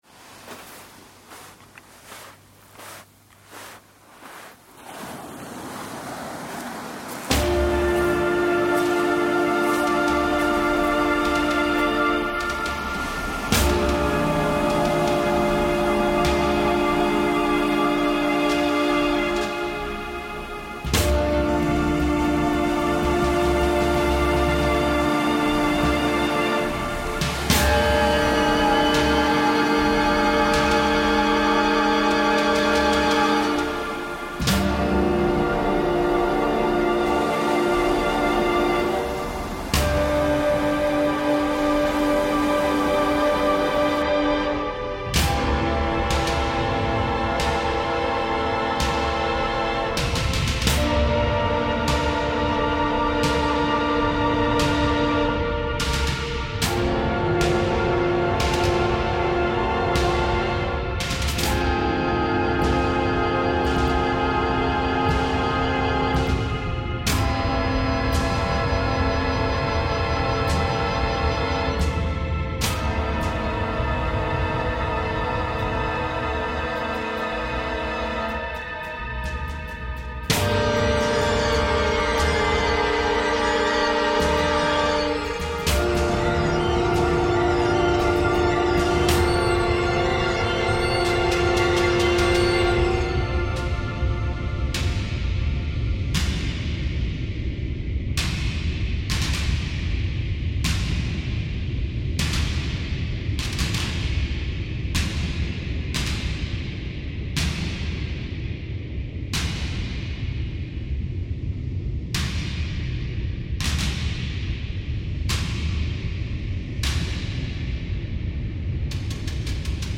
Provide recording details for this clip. Napier beach reimagined